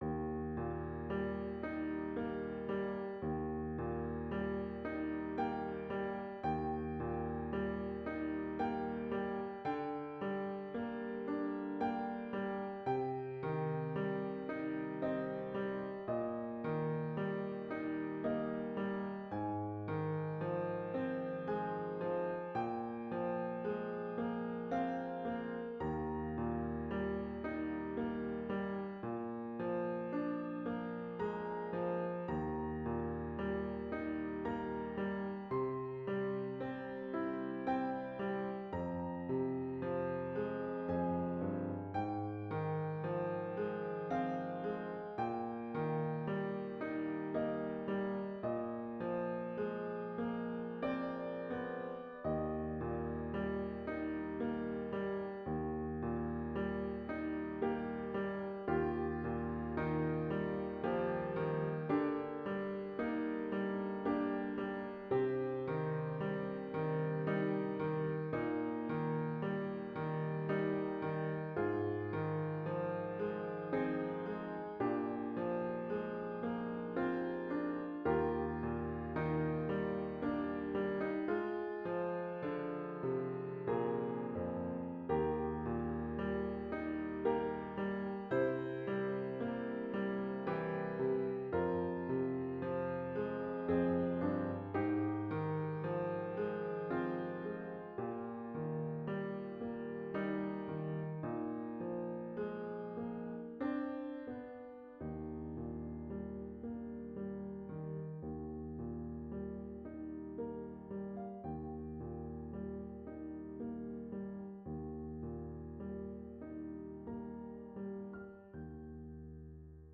Piano Solo (early intermediate)